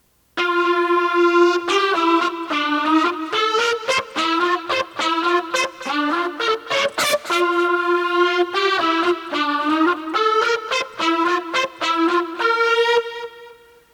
Achievement unlocked! I got my trumpet audio processing through my synth.
Just a short clip of me testing it out with a chorus, delay, and reverb.